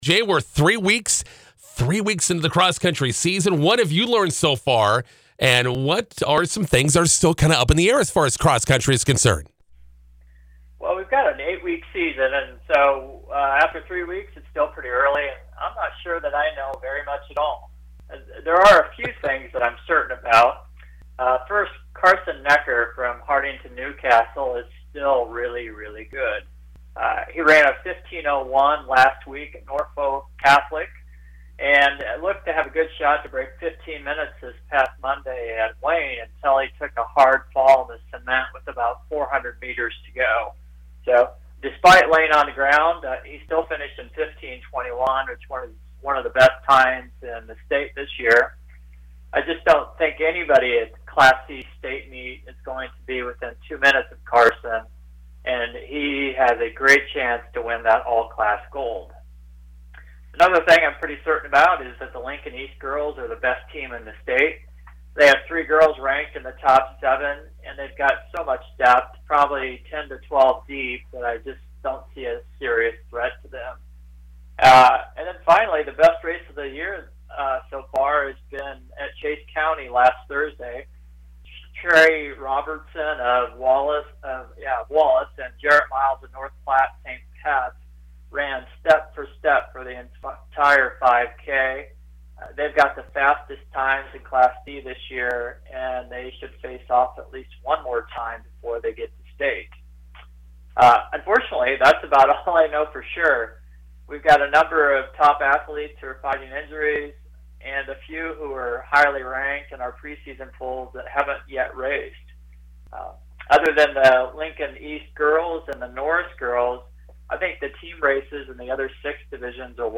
McCook radio interview 9/14/22